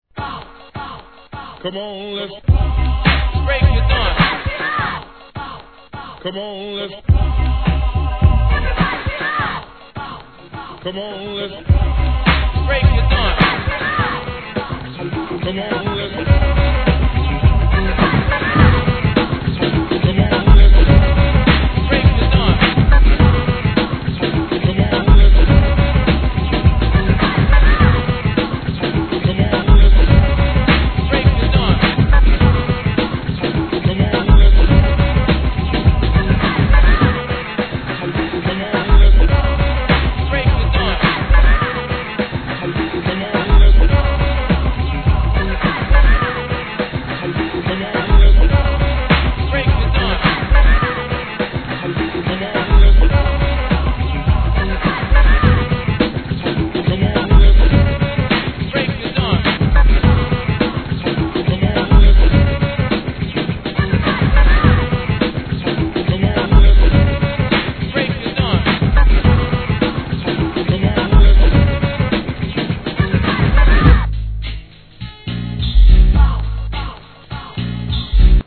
1. HIP HOP/R&B
1996年、アンダーグランドなトラック物!